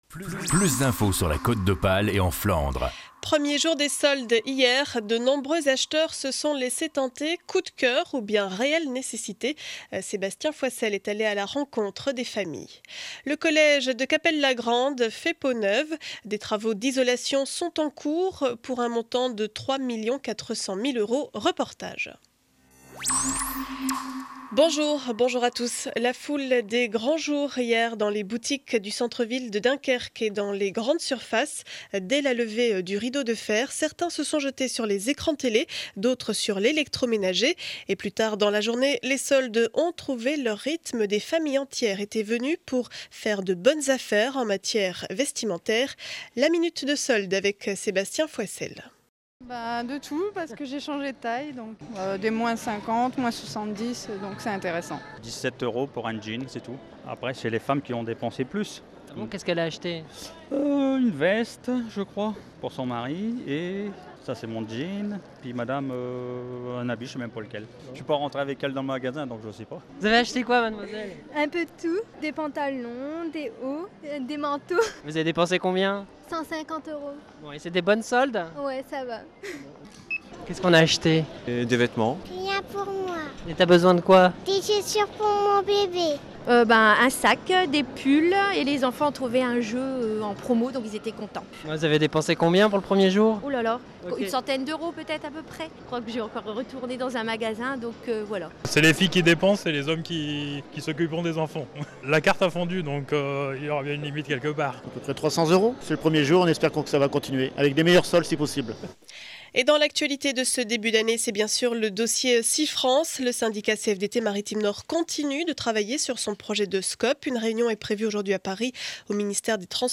Journal du jeudi 12 janvier 12 heures édition du Dunkerquois.